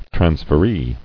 [trans·fer·ee]